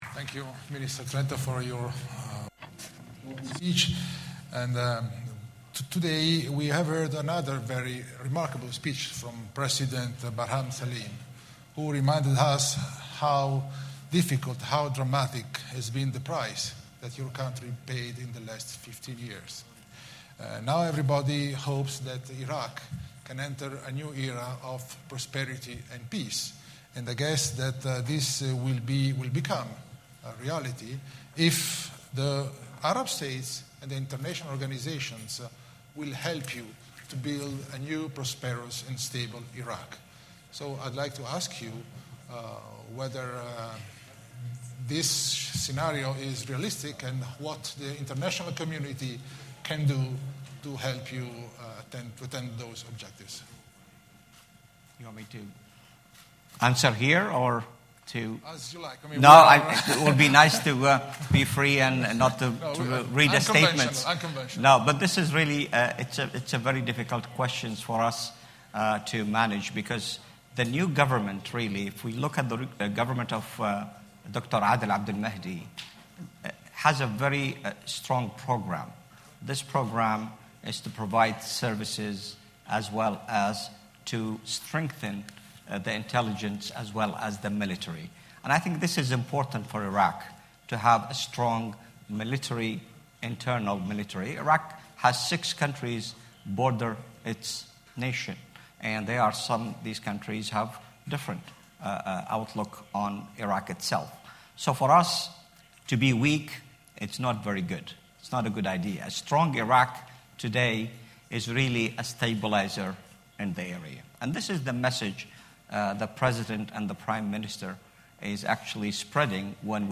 Speaking on a panel with Italian Defence Minister Elisabetta Trenta and Iraqi Foreign Minister Mohamed Ali Alhakim, the Secretary General outlined NATO’s role in the fight against terrorism and emphasised the importance of training local forces to secure peace.